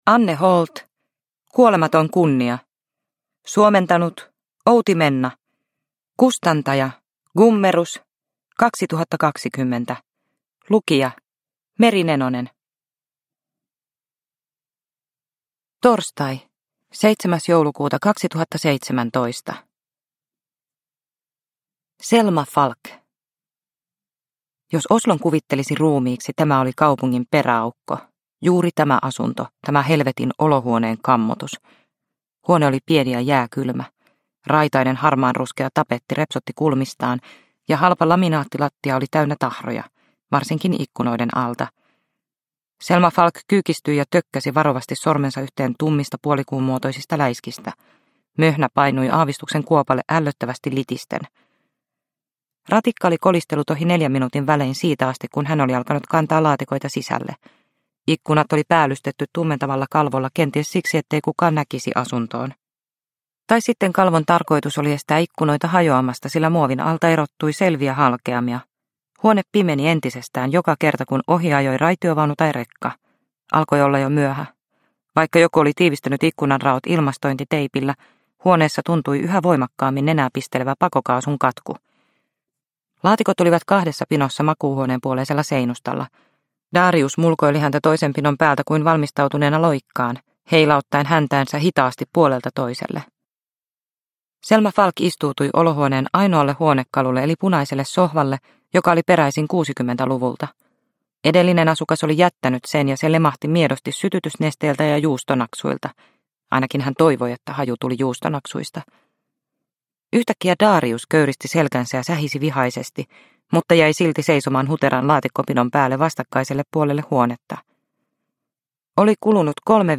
Kuolematon kunnia – Ljudbok – Laddas ner